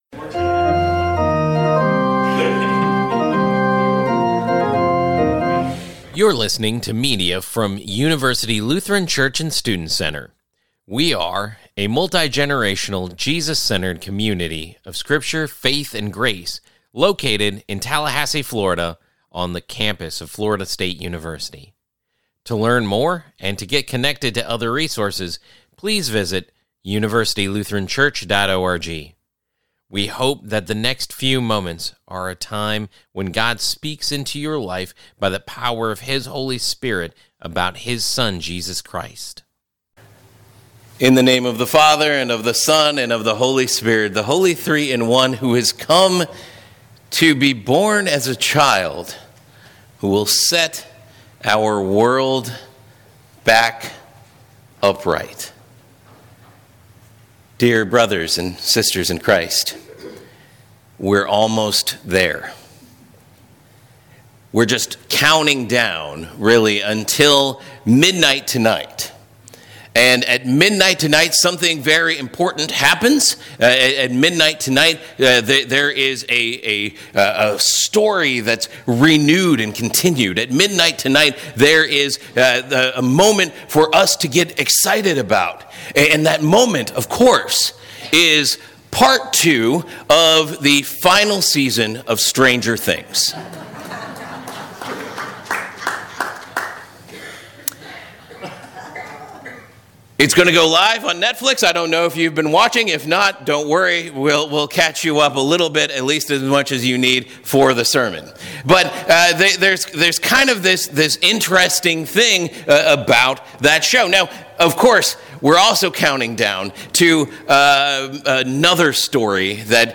If you haven’t, don’t worry—we’ll catch you up just enough for the sermon.